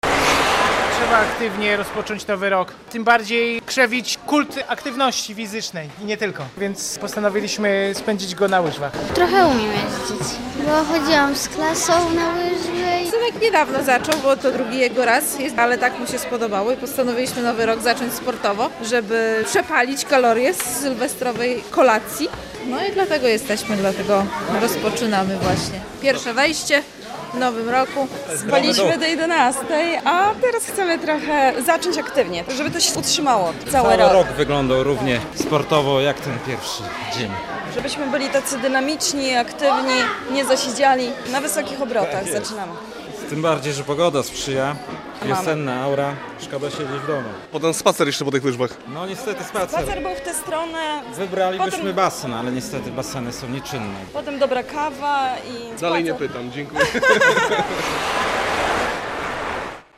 Nowy Rok na łyżwach - relacja